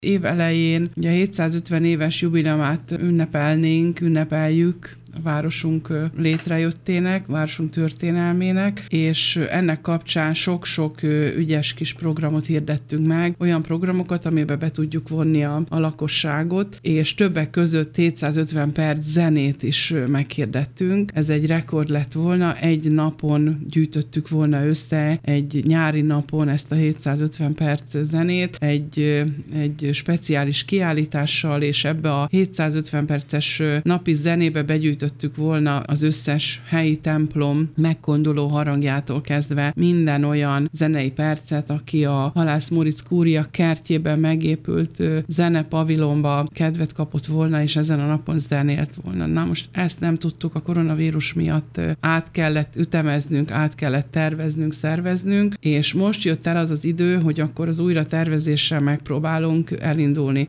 Ma elkezdődik a hangok gyűjtése 19 órakor a Halász Móricz-kúria zenepavilonjában, ahol fröccsel és sajtkóstolóval várják a közönséget a Friday Band zenészei. Pálinkásné Balázs Tünde alpolgármestert hallják.